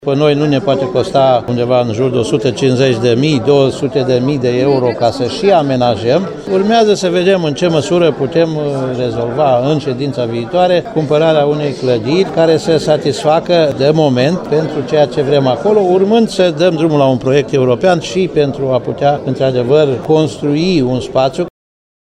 Potrivit presedintelui Consiliului Judeţean Timiş, Titu Bojin, administratia va efectua un studiu de oportunitate privind achizitia unui imobil care sa fie amenajat ca muzeu: